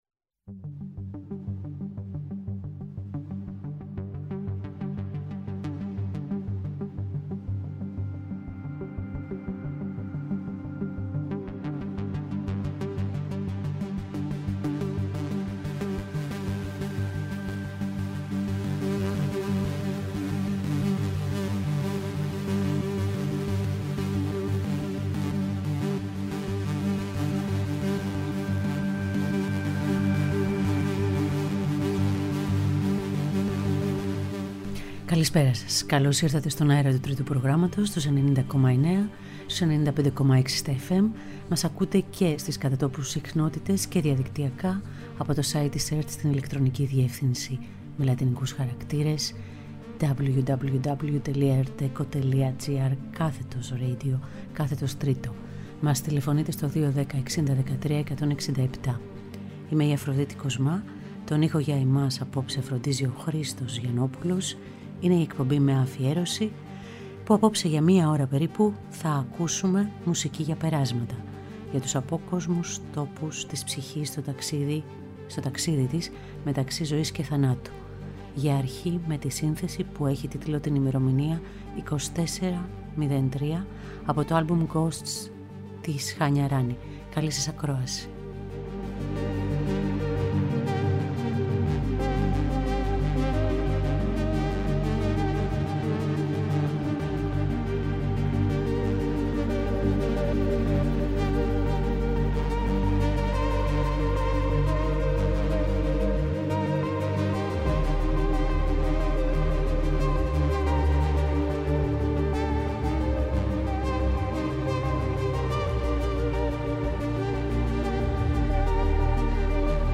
σε ένα ψιθυριστικό ναναούρισμα